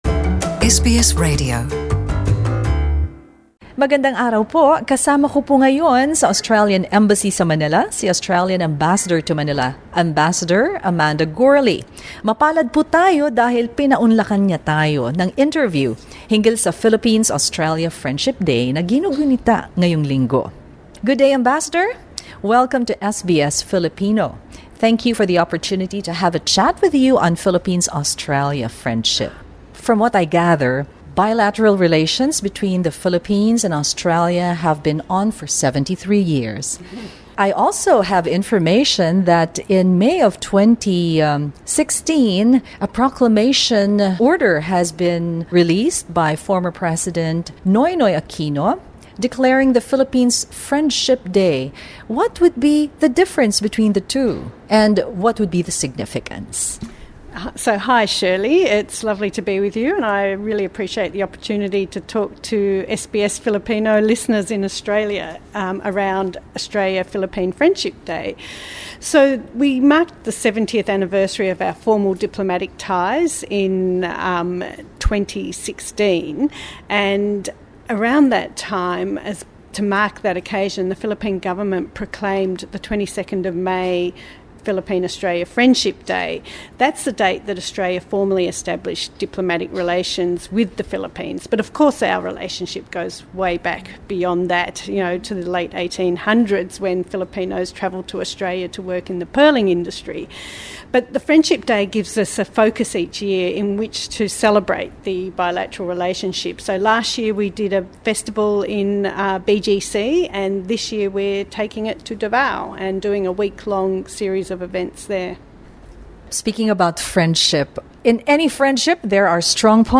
Ipinahayag ng Ambassador ang mga nagpapatibay sa ugnayan ng dalawang bansa kabilang na ang kooperasyon sa pagtatanggol at anti-terorismo at proseso ng pagsulong ng kapayapaan sa mga rebelde.